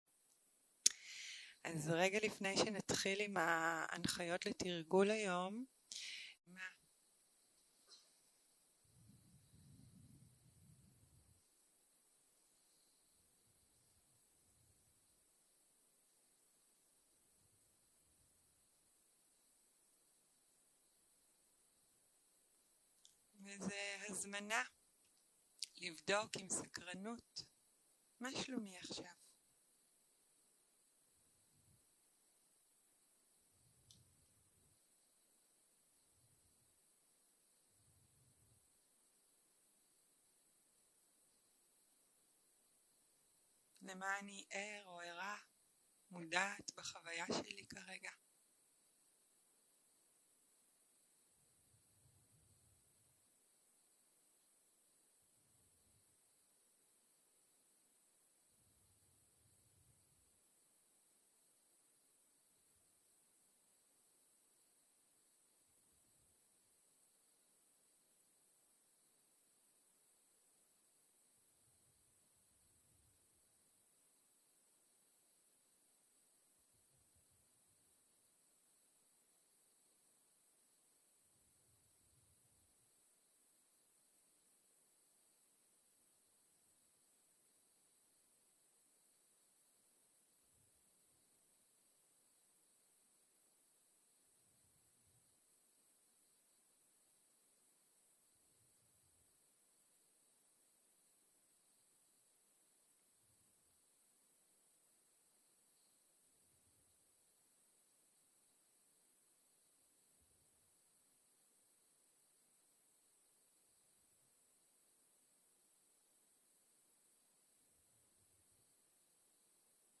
שיחת הנחיות למדיטציה